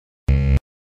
Звуки ошибок
На этой странице собраны разнообразные звуки ошибок из операционных систем, программ и игр.
10. Бип